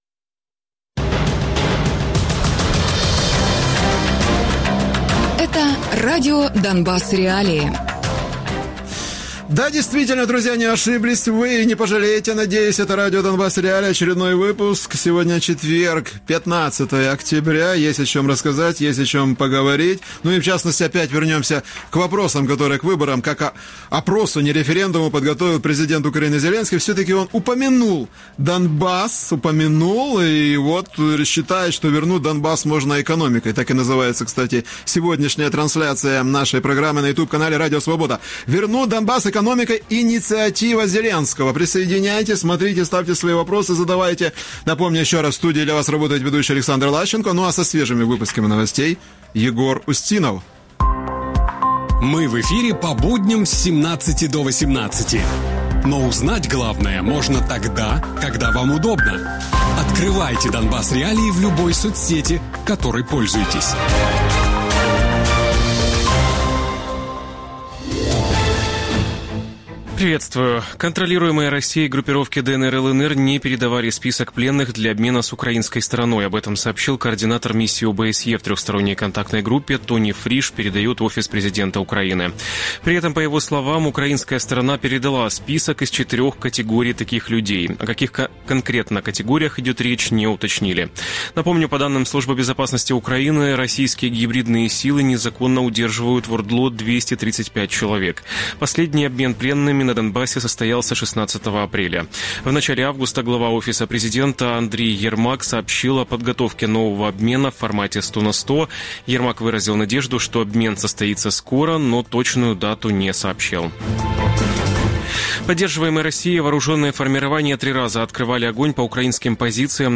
Гості програми: Сергій Тарута - народний депутат, екс-голова Донецької області